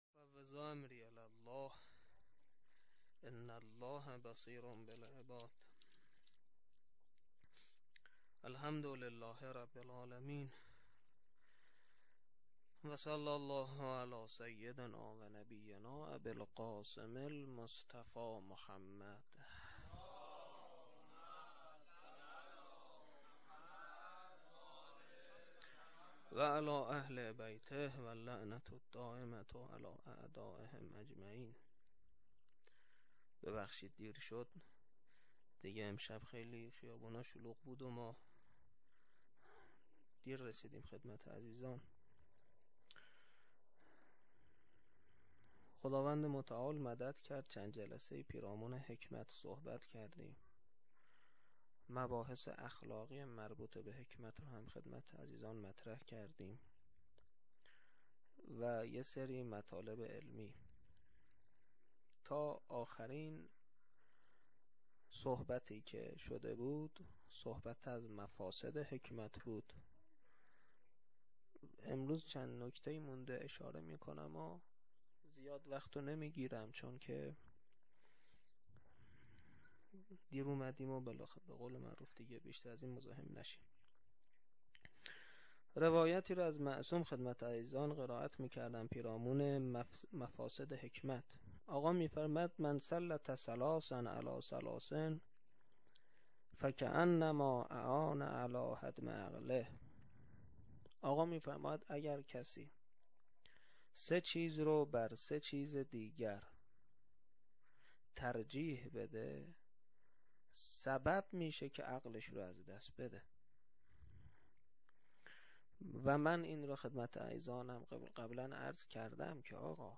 سخنرانی.mp3